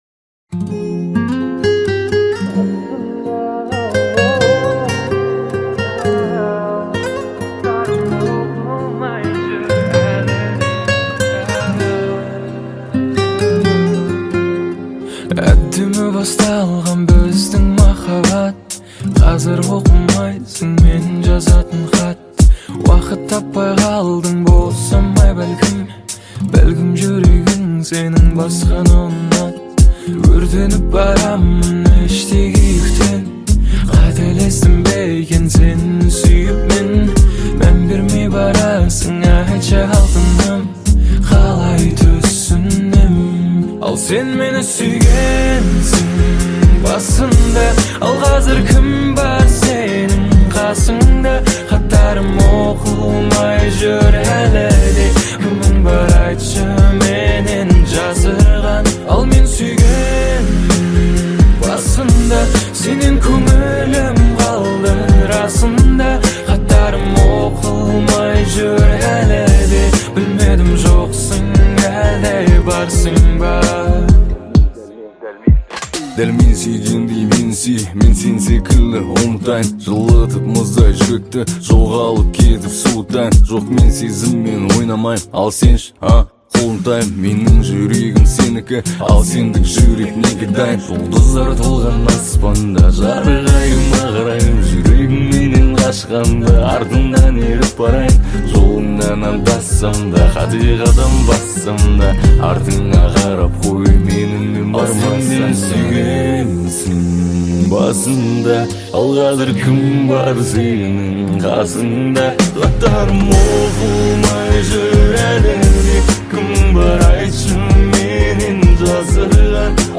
• Категория: Казахская музыка